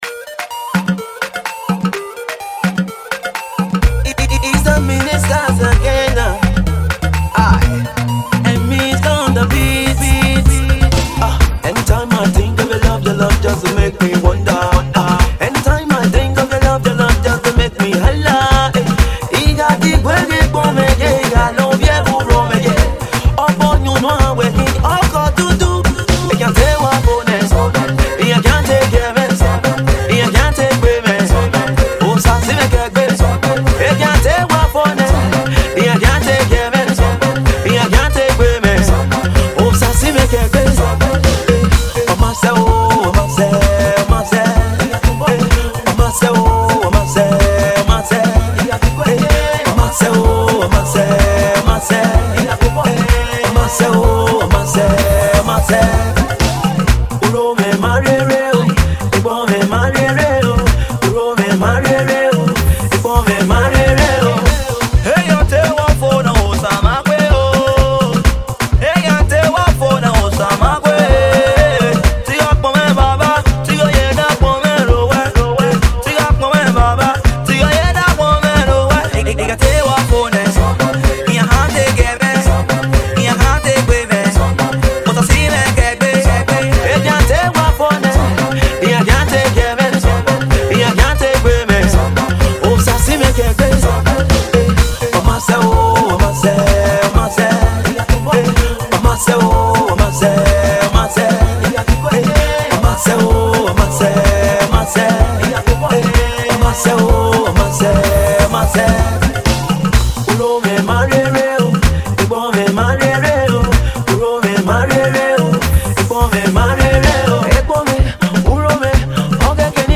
A group Strictly for gospel & Spiritual Songs.